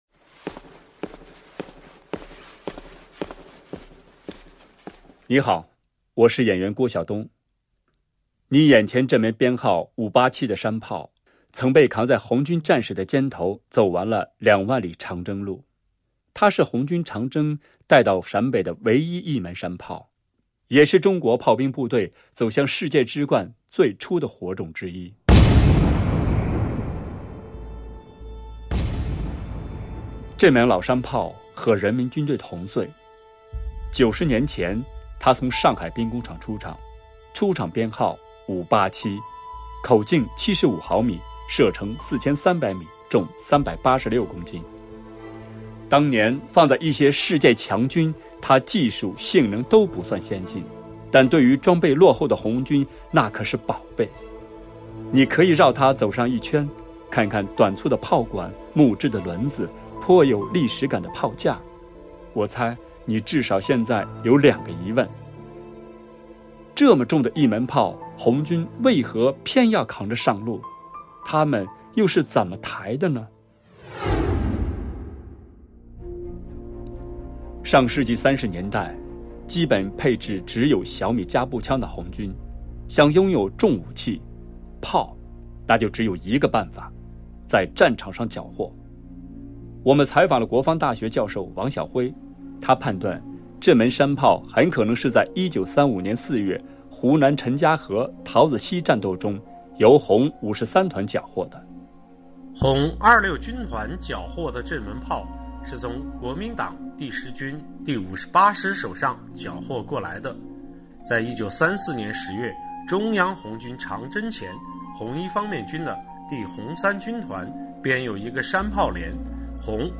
关键词:CNR,中国之声,大国军藏,收藏,解说,军事
《大国军藏》十件珍品的声音导览。